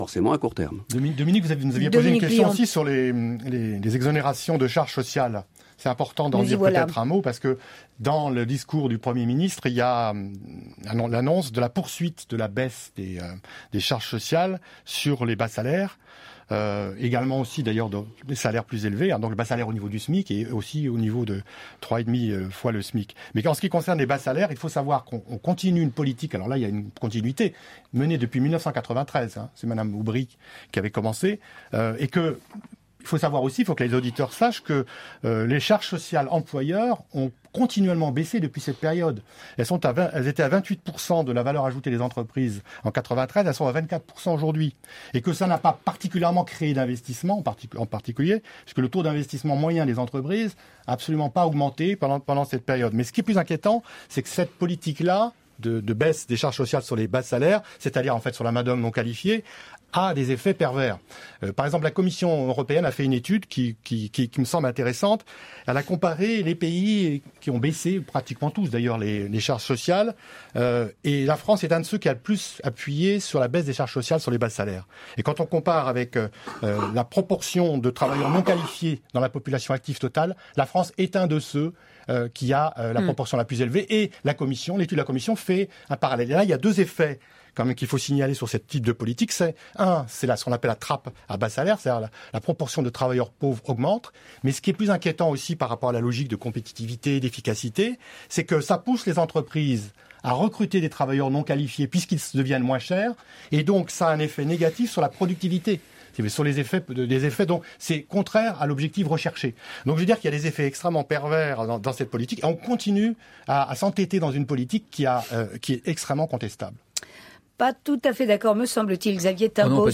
Extrait de l’émission L’économie en question, émission du 12 avril 2014, « Examen des mesures annoncées par Manuel Valls ».